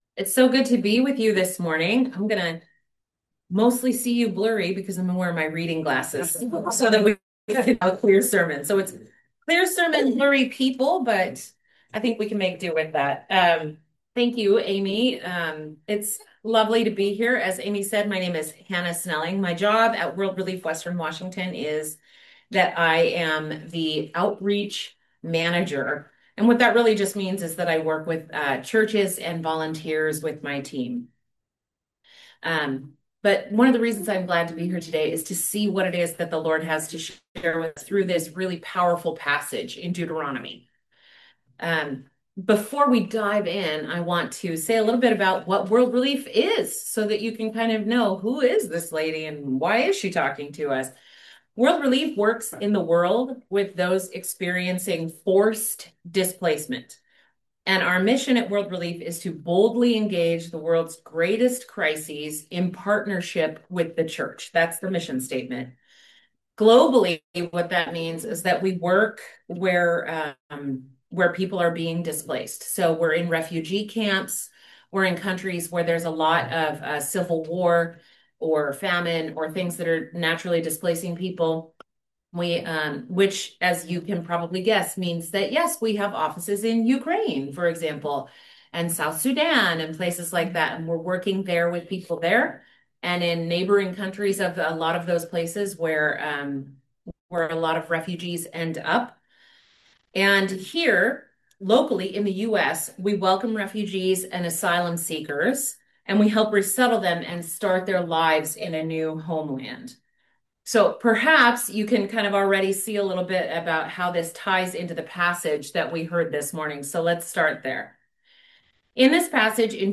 Guest Preacher